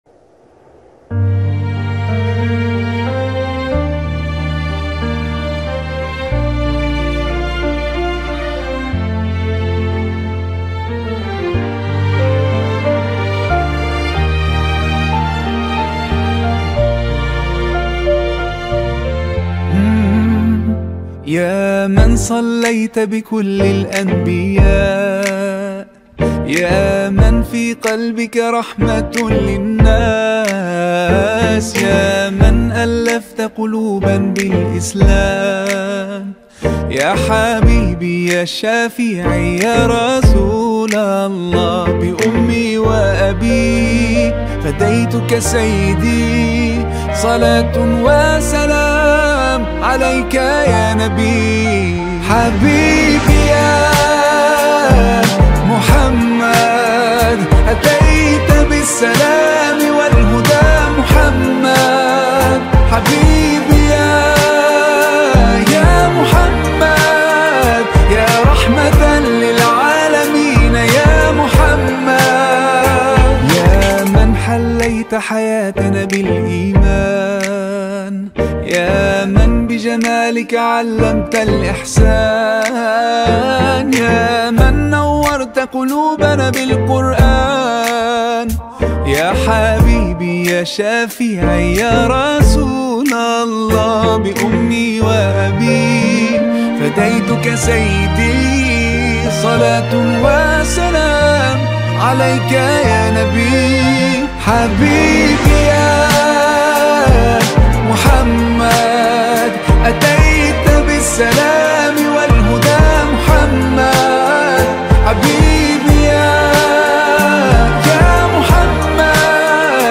Arabic Songs , Nasyid Songs